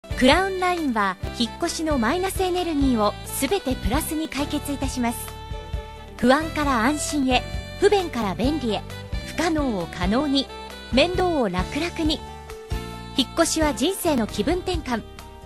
Professionelle japanische Sprecherin für TV / Rundfunk / Industrie.
Kein Dialekt
Sprechprobe: Werbung (Muttersprache):